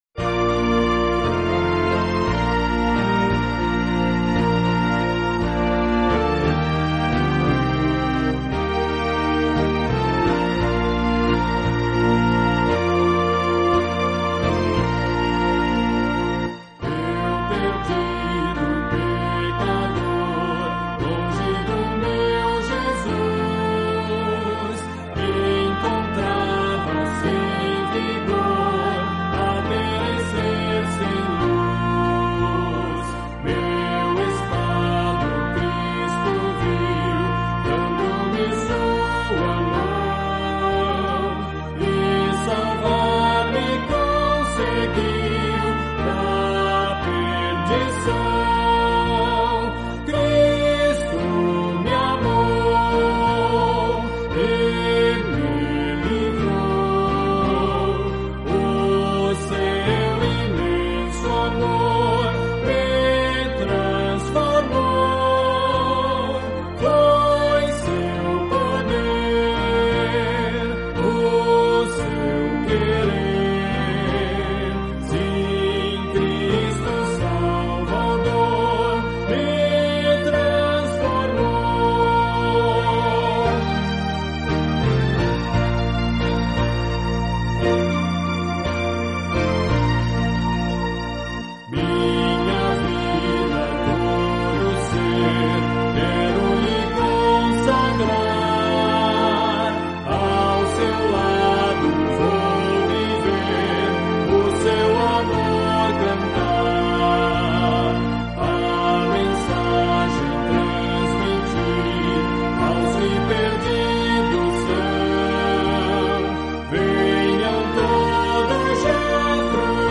Esses dedinhos nos os gravamos aos microfones da Rádio Você, em Americana, interior do Estado de São Paulo.